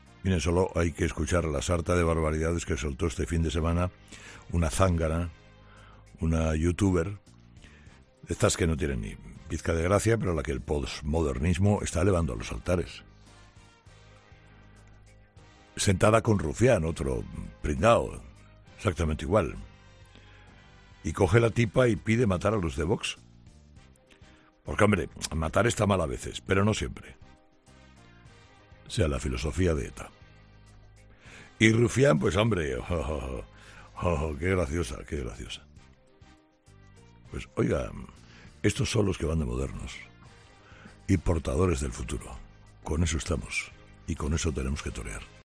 Este lunes, Carlos Herrera también ha hablado sobre esta cuestión.